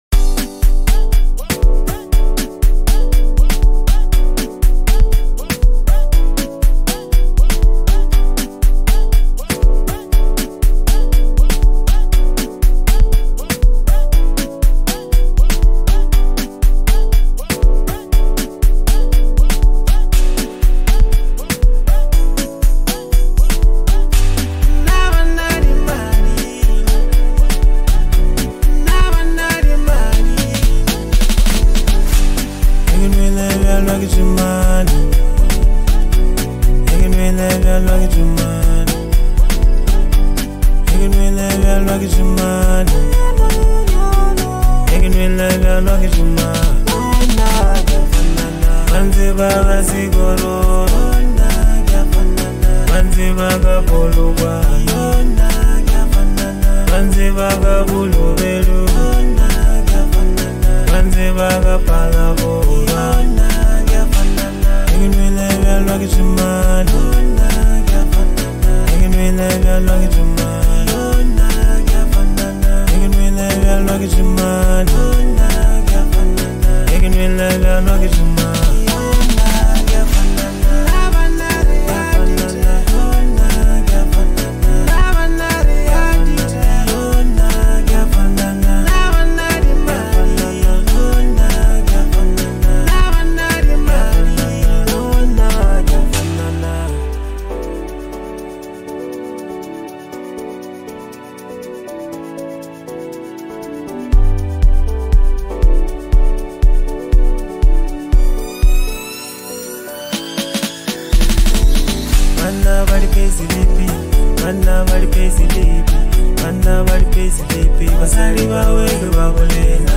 passionate and emotive track